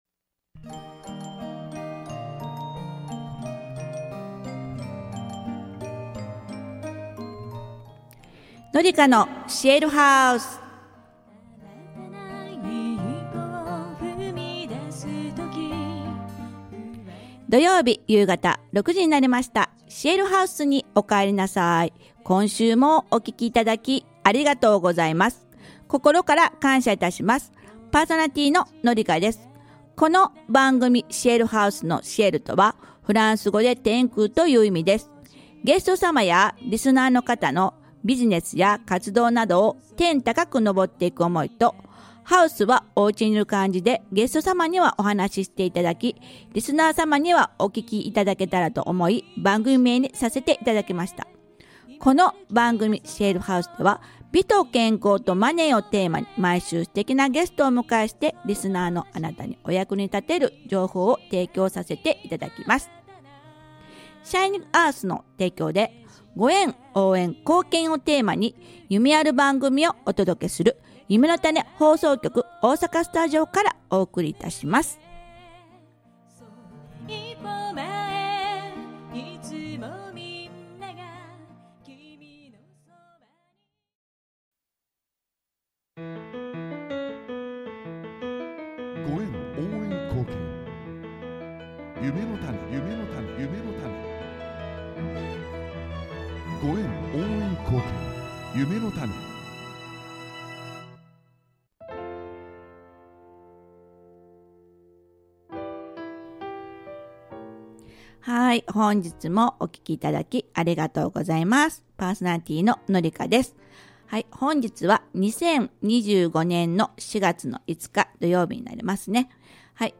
ゆめのたね ラジオ ゲスト出演